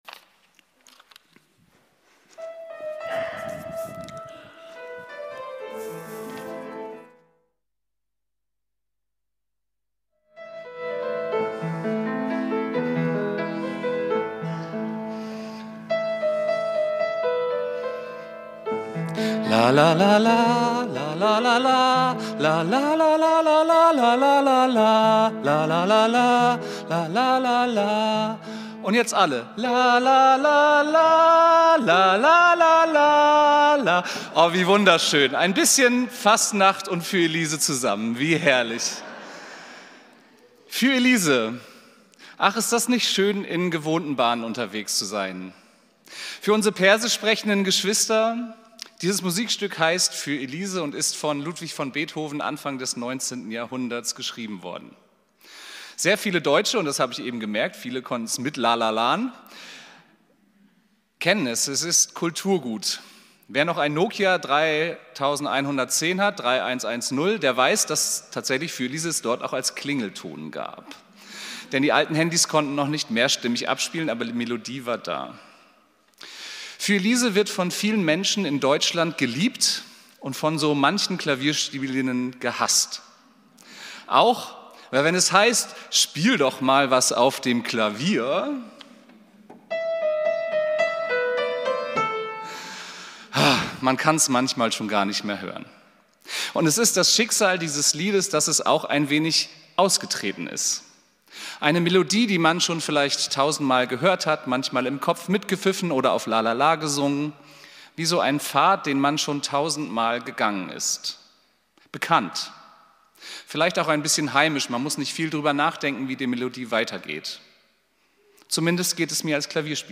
Predigt vom 02.03.2025